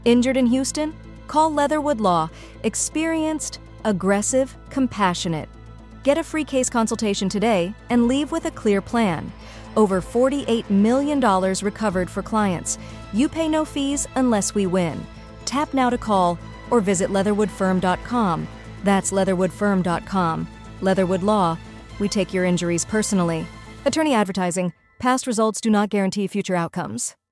Creative: Calm + clear + direct CTA (call/tap)
Leatherwood Law — sample Houston PI spot.
leatherwoodfirm_radio_ad_for_blog.mp3